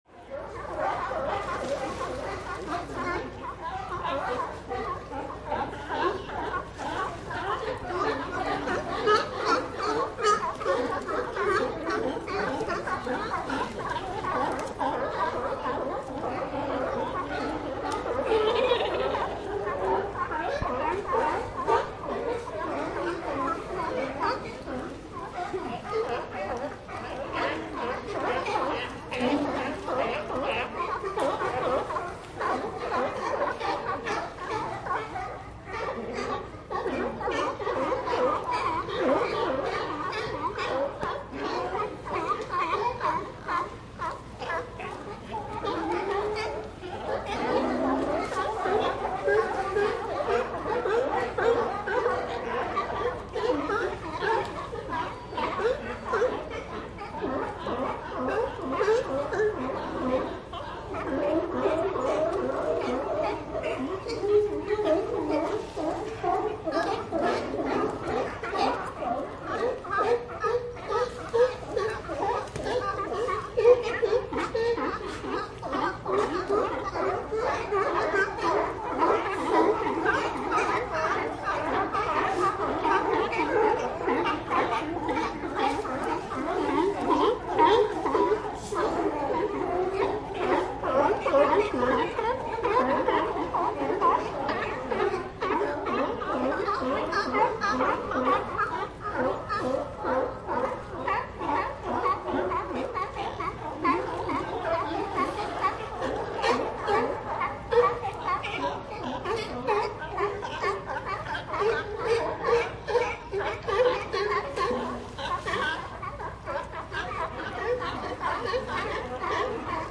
Sea Lions At Pier Bouton sonore